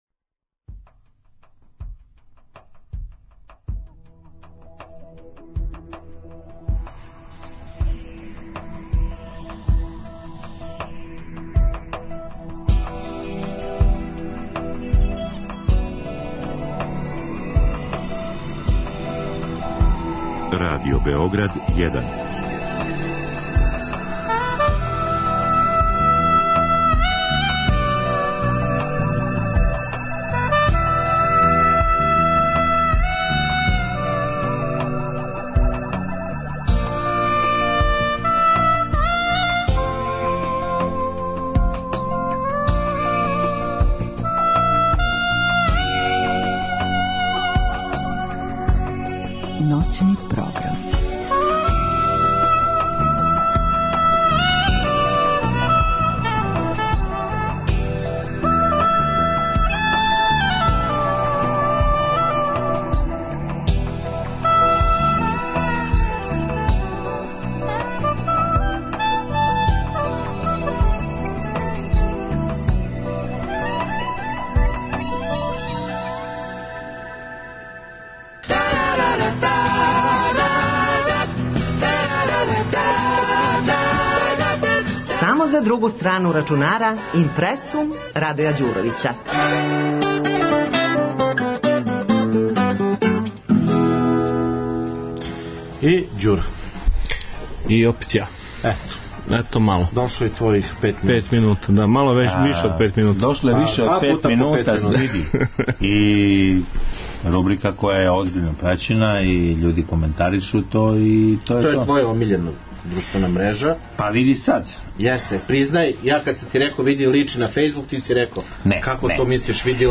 "LinkedIN" je bila tema stalne rubrike IMPRESSUM, emisije "Druga strana računara" noćnog programa Radio Beograda 1, emitovana 26. Jula 2014.godine.